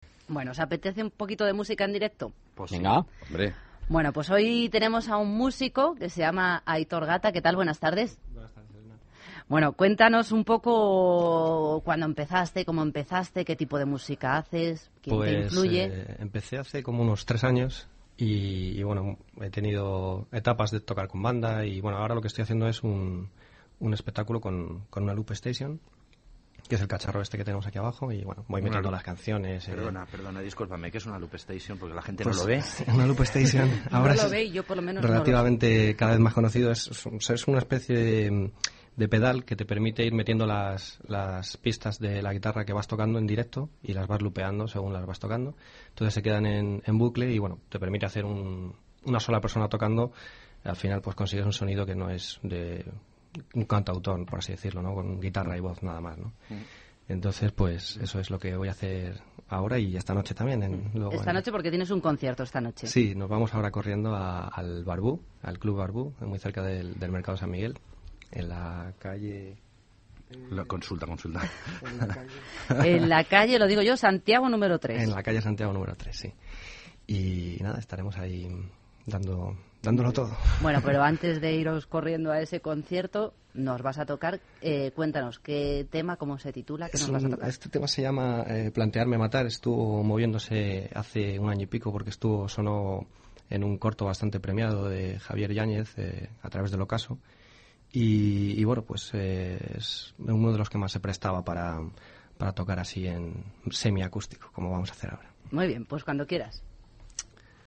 Entrevista Cadena SER